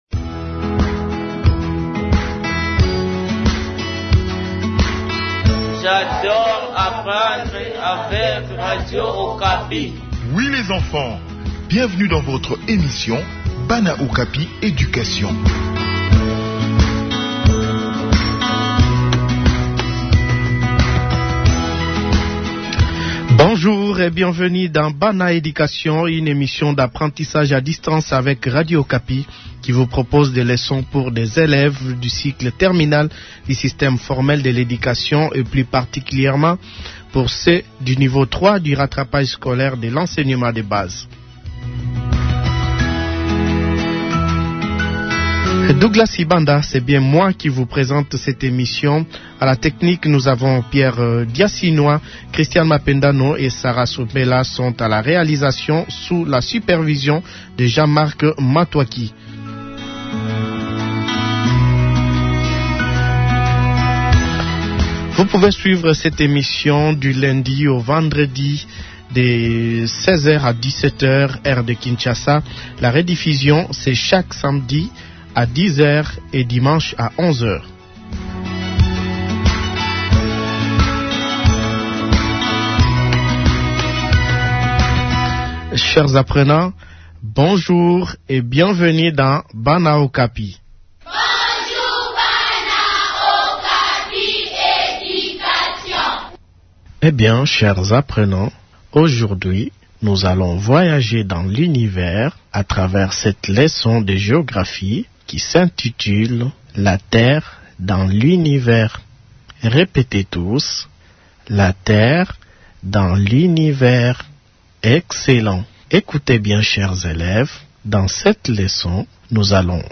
Enseignement à distance : leçon de Géographie sur le système solaire